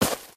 sounds / material / human / step / t_gravel4.ogg
t_gravel4.ogg